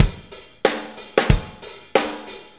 Shuffle Loop 28-11.wav